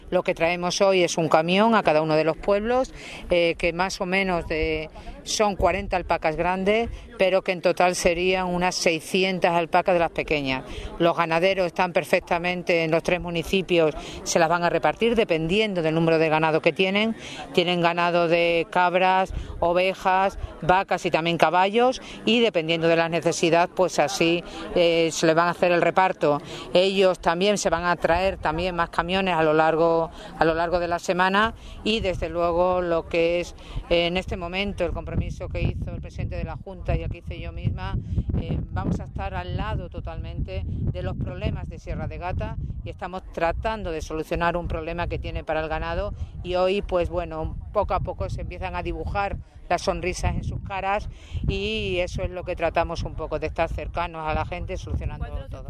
CORTES DE VOZ
CharoCordero_heno.mp3